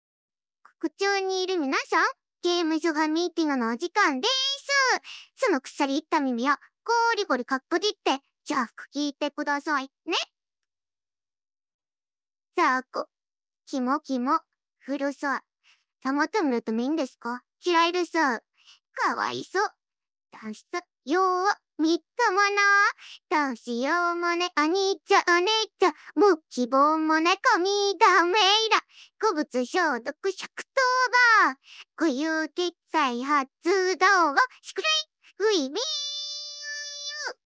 唱歌表现